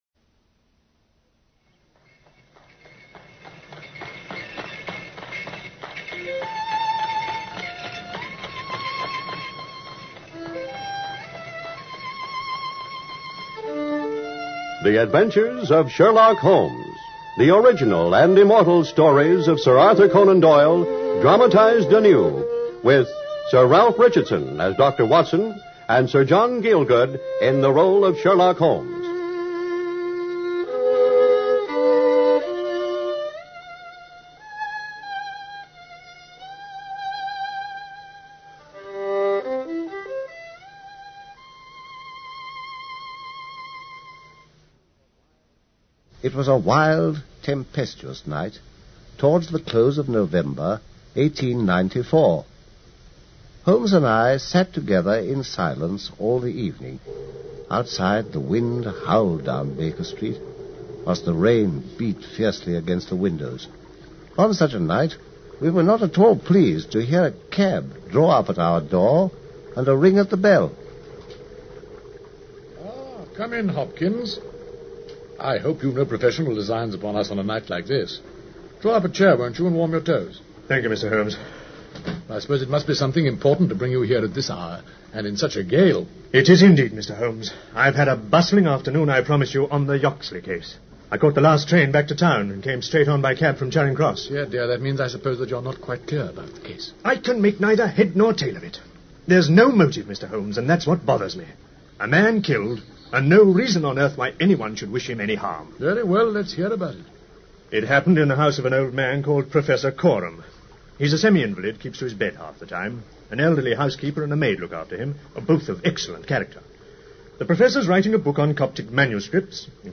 Radio Show Drama with Sherlock Holmes - The Golden Pince Nez 1954